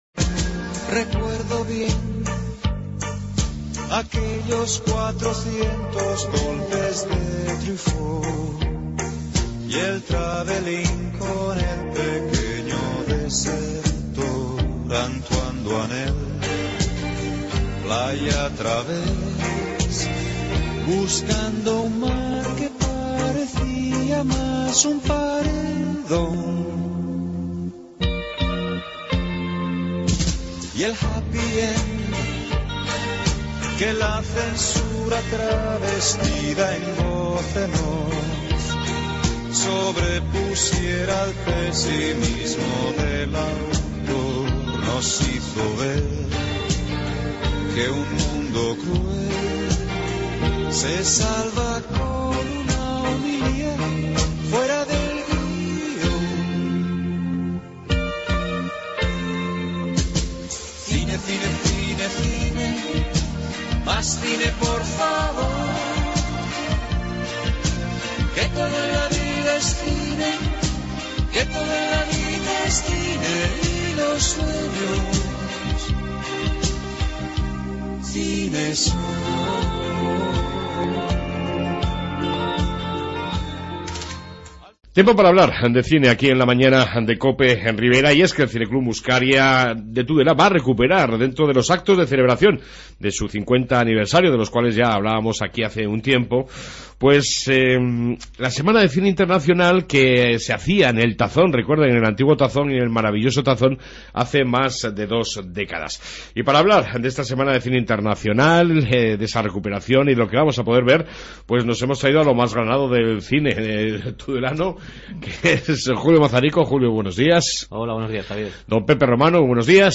AUDIO: En esta 2 parte Entrevista con el Cine Club Muskaria con motivo del Festival Internacional de Cine